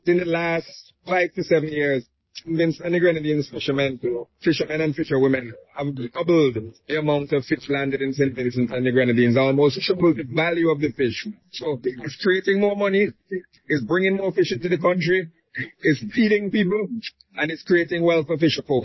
But, in a speech to open the 47th Annual Fisherman’s Day competition on May 27, Finance Minister Camillo Gonsalves noted: “The fish stock in St.  Vincent and the Grenadines has doubled in comparison to that of other years.”
camilo-fish-stock-audio-denoised.mp3